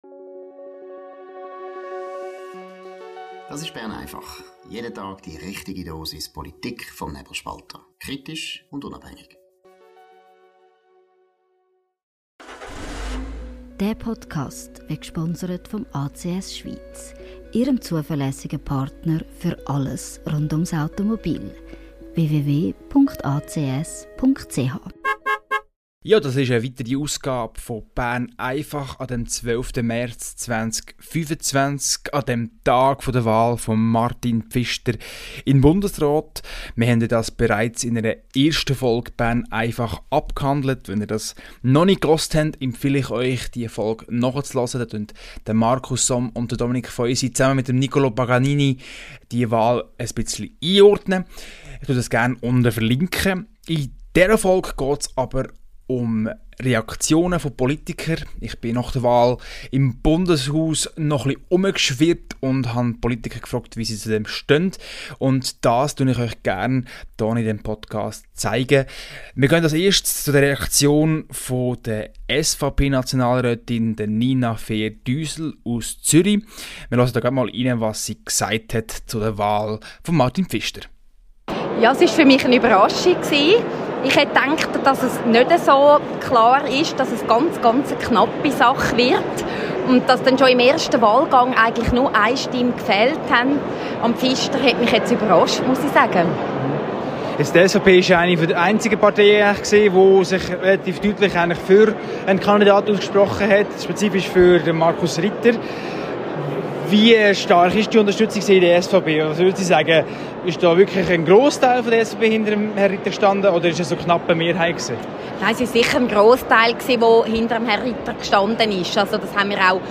hat Politiker aus verschiedenen Parteien zur Wahl von Martin Pfister befragt.